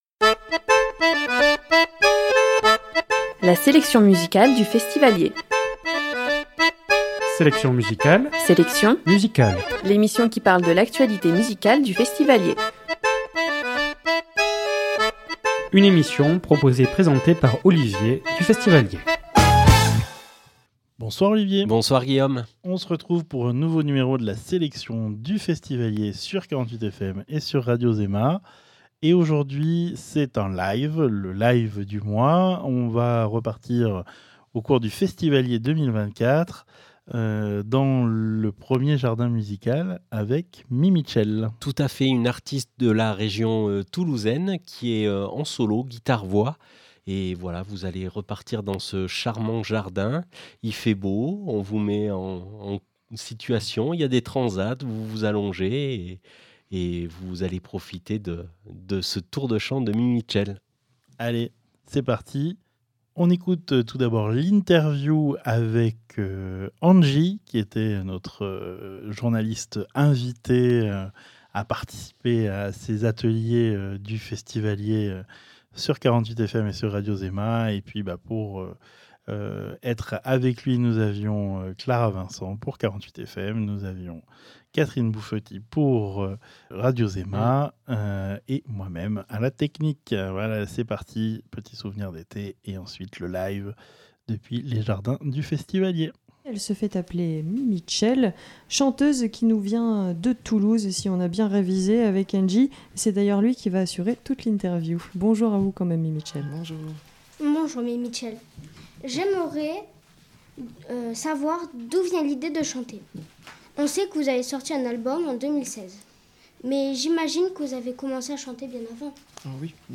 Interview
à Langogne